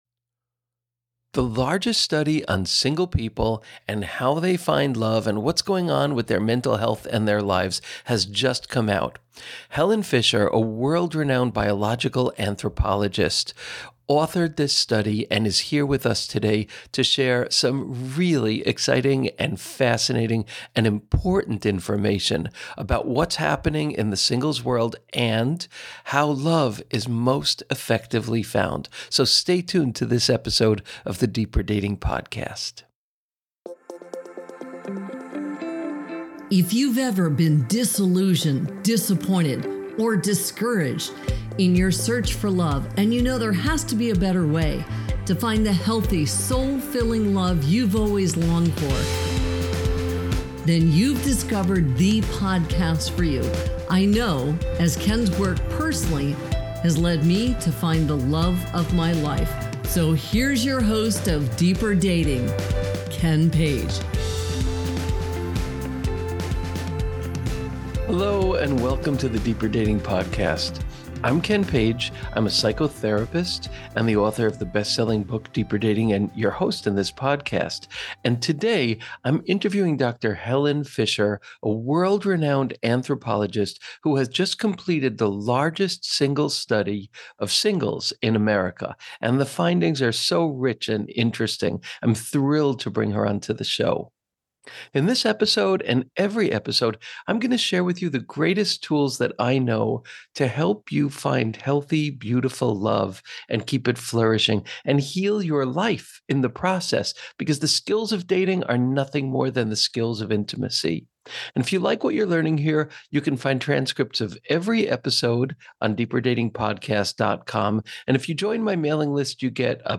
The largest study of single people and the search for love has just come out! In this episode, I interview Dr. Helen Fisher, renowned anthropologist w